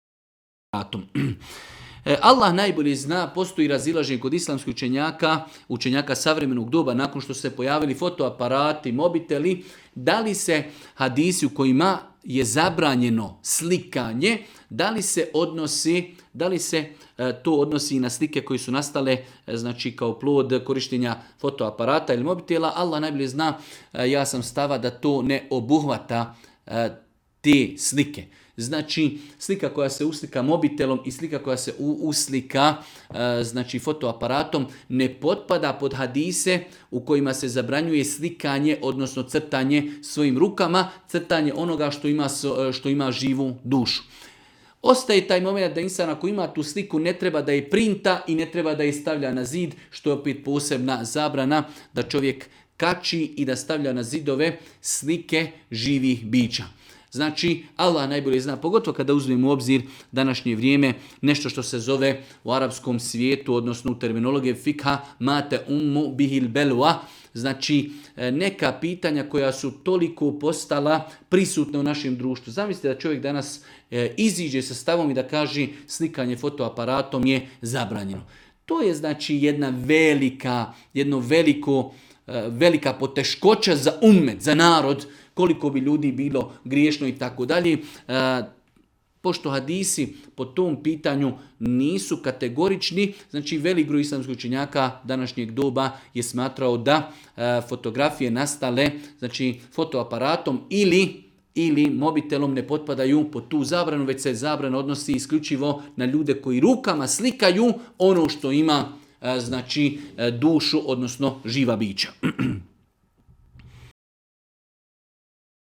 u video predavanju ispod